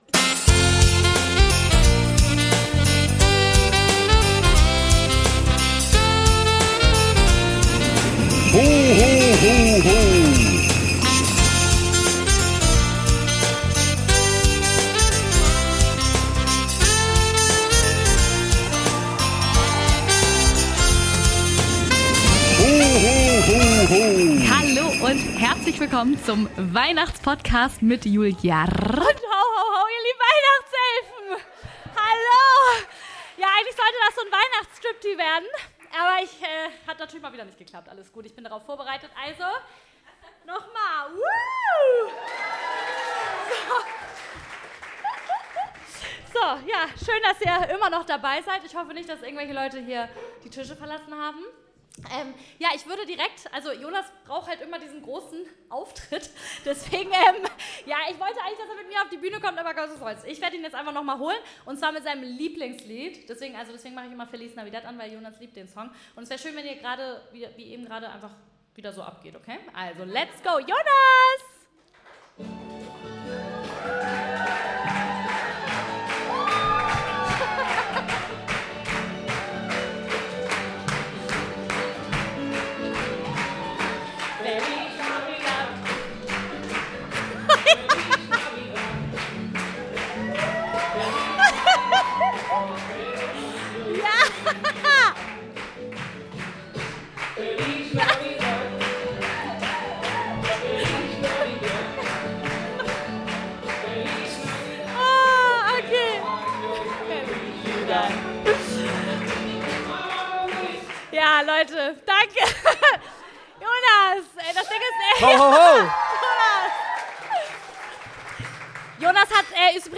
Die zweite Halbzeit des Live Auftritts. Hier handelt es sich um Weihnachts Fails.